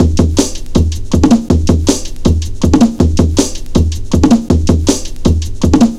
Index of /90_sSampleCDs/Zero-G - Total Drum Bass/Drumloops - 1/track 17 (160bpm)